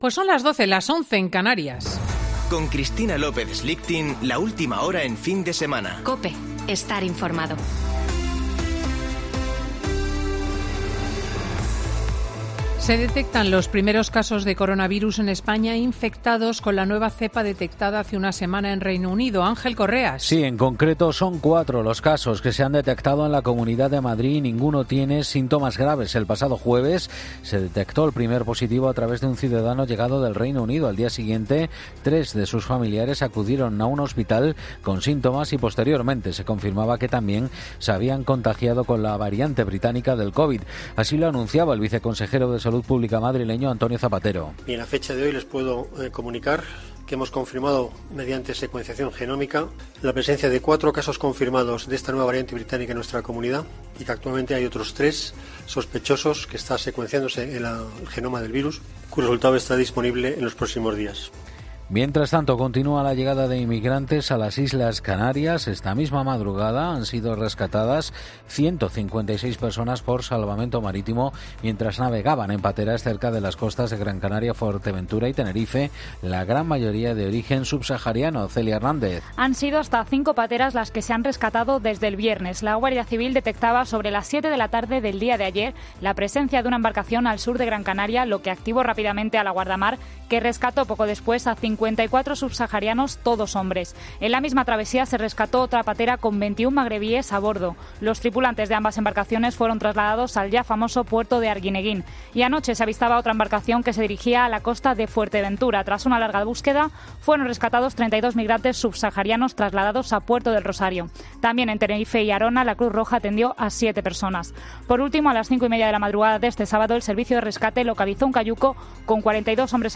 Boletín de noticias de COPE del 26 de diciembre de 2020 a las 12.00 horas